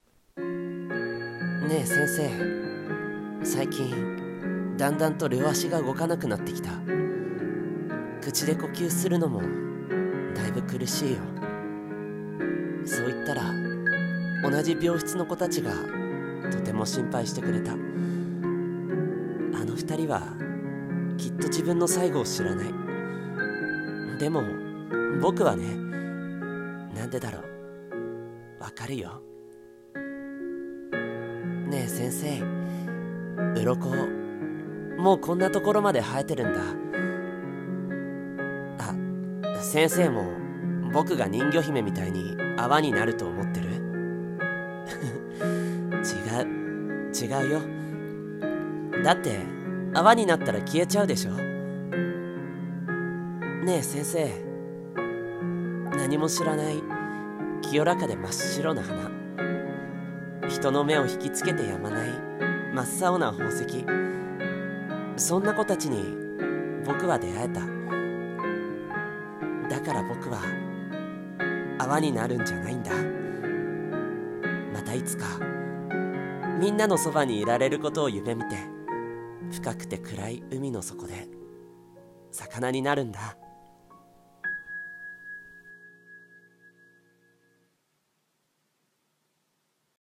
【一人声劇】深海魚